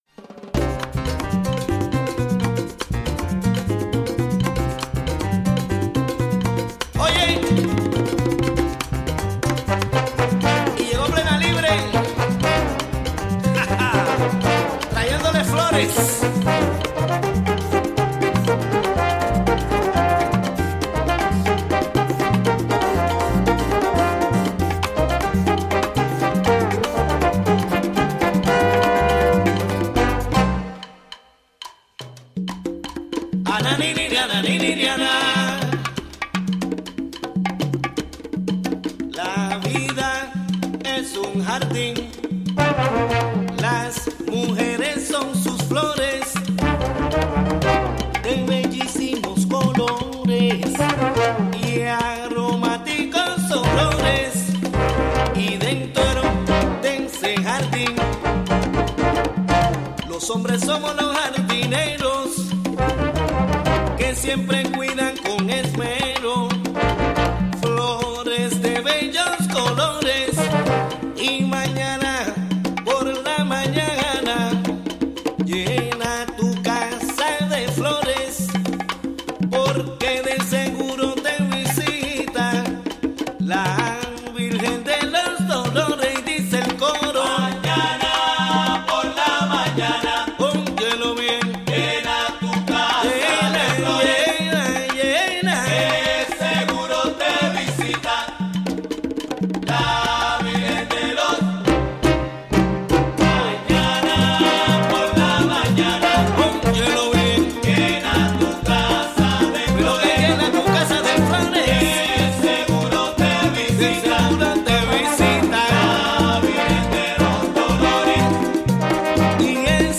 11am Un programa imperdible con noticias, entrevistas,...